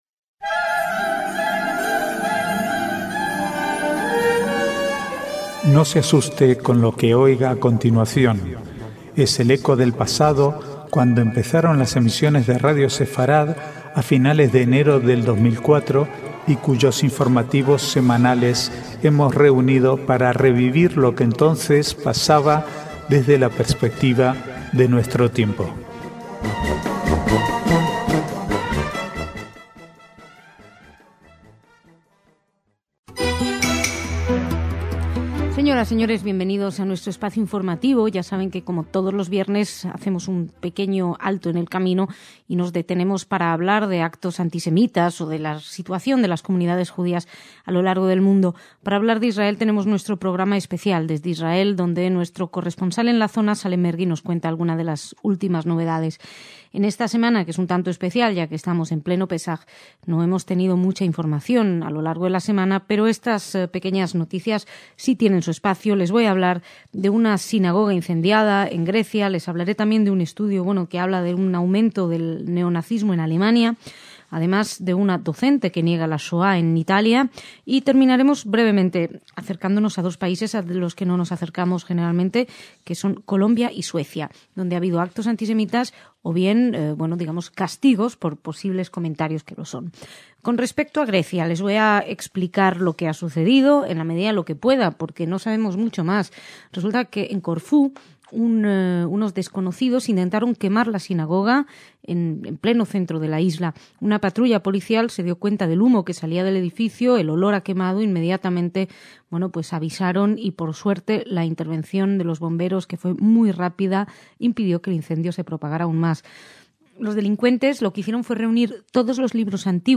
Archivo de noticias del 22/4 al 3/5/2011